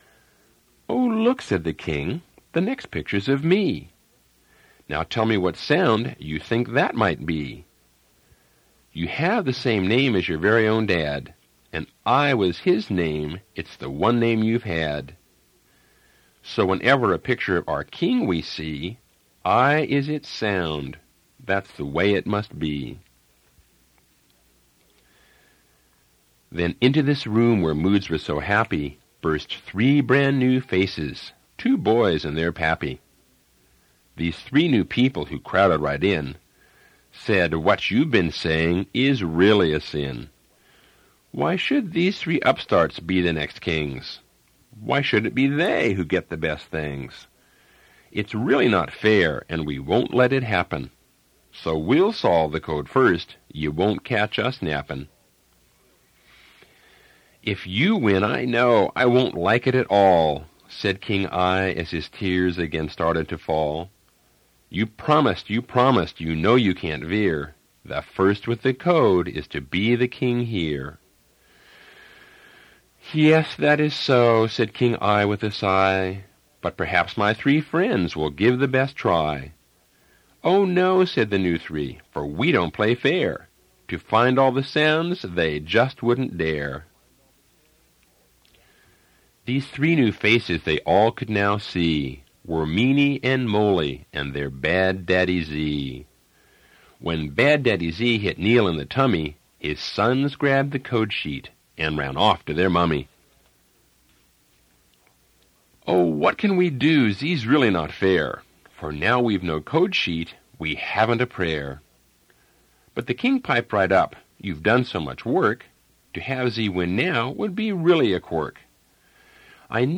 Reading of Dekodiphukan